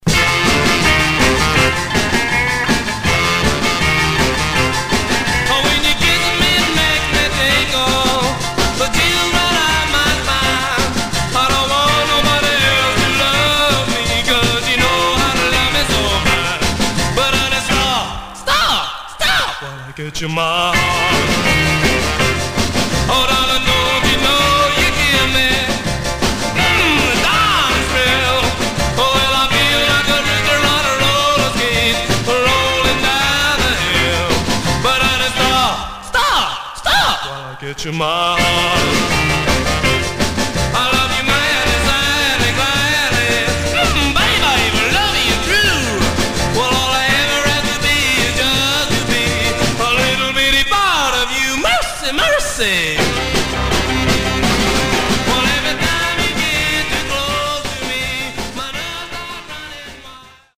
Stereo/mono Mono
Rockabilly Condition